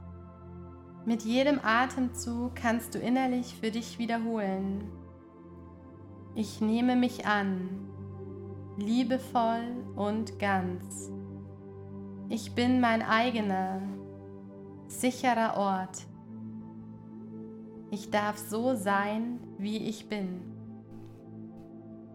• Format: Geführte Meditationen (Audio-Dateien)